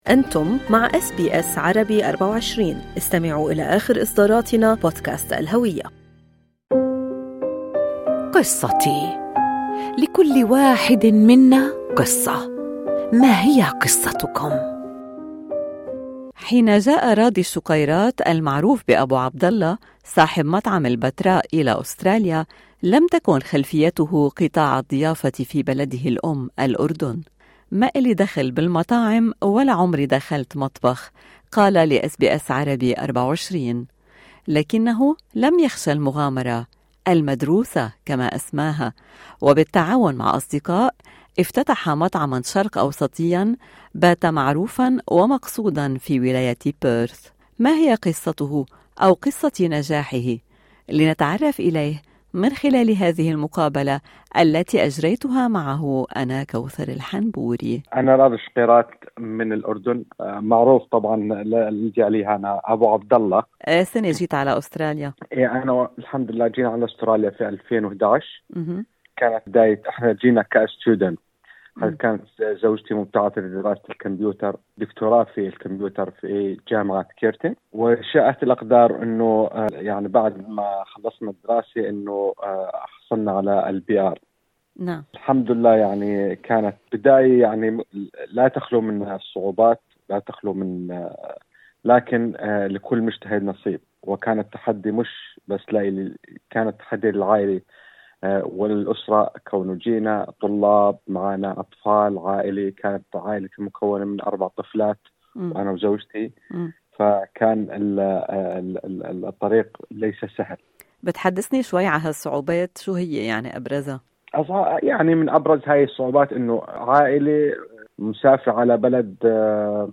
ما هي قصته أو قصة نجاحه لنتعرف اليه من خلال هذه المقابلة في الملف الصوتي أعلاه.